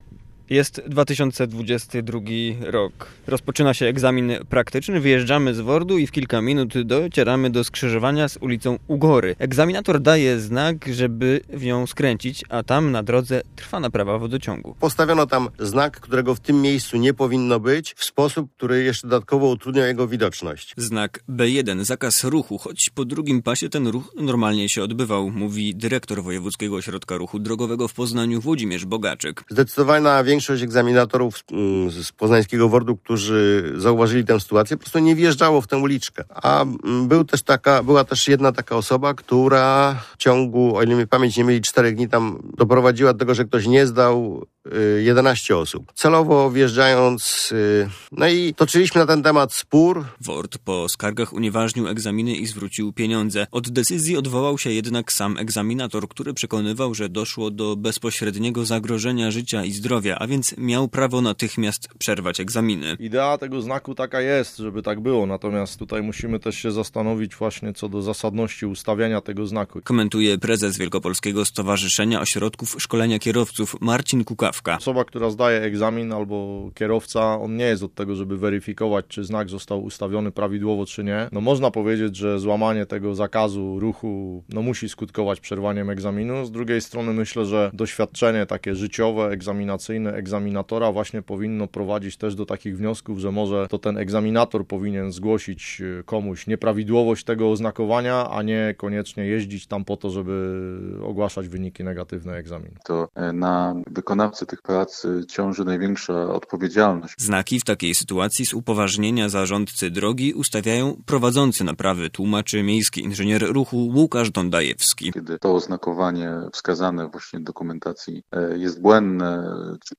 KOMENTARZ EGZAMINATORA